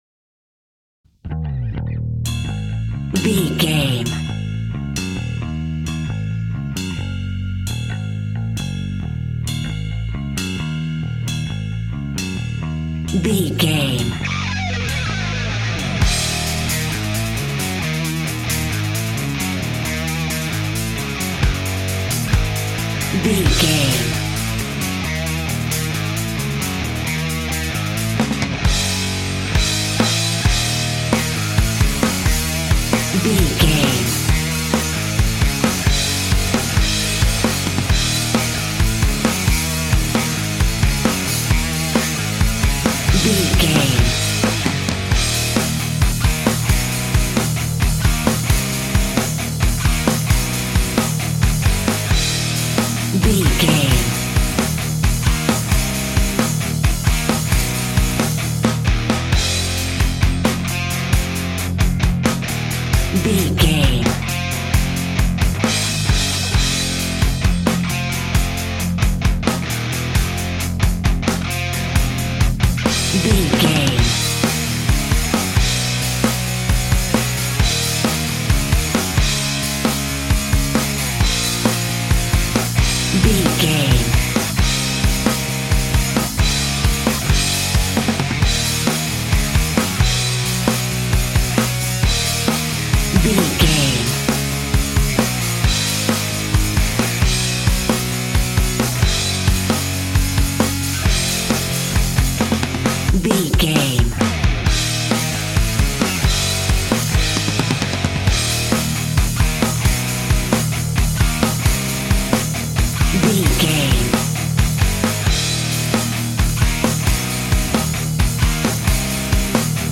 Ionian/Major
E♭
energetic
driving
heavy
aggressive
electric guitar
bass guitar
drums
hard rock
heavy metal
instrumentals
distorted guitars
hammond organ